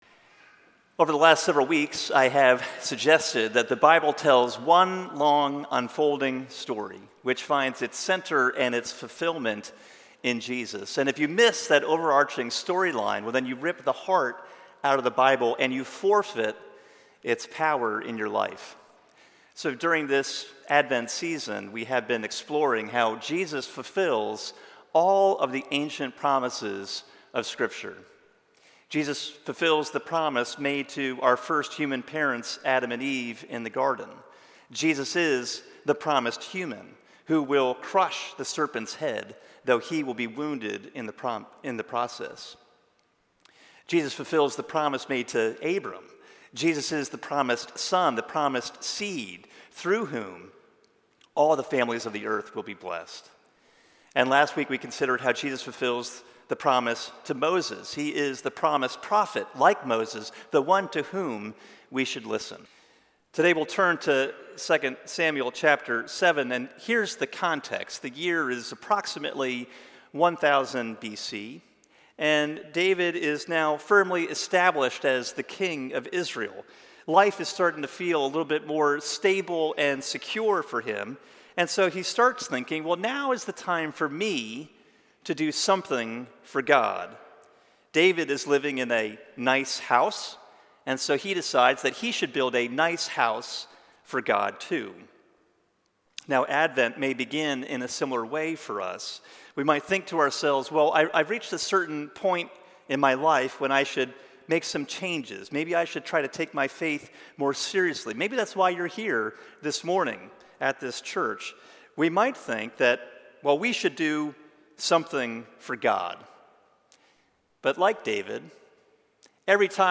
View Sermon Transcript Download .pdf Over the last several weeks I have suggested that the Bible tells one long unfolding story, which finds its center and its fulfillment in Jesus.